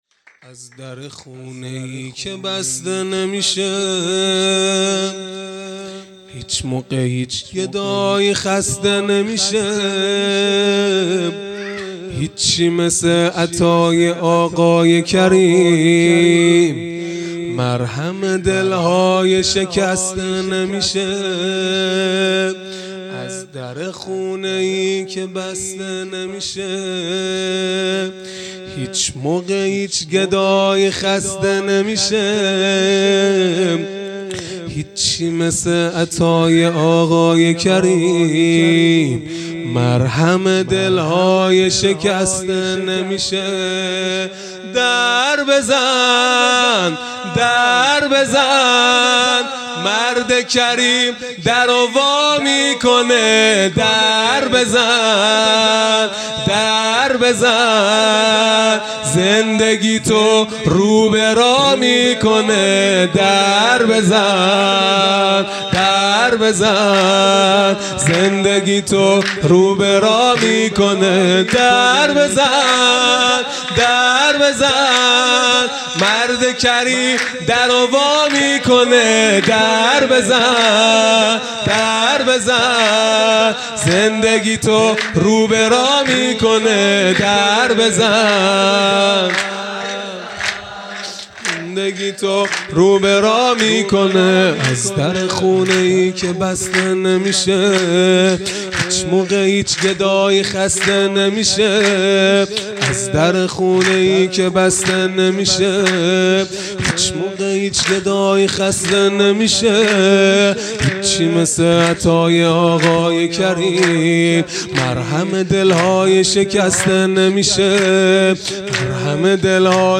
0 0 سرود | از در خونه ای که بسته نمیشه
جلسۀ هفتگی به مناسبت میلاد امام حسن مجتبی(ع)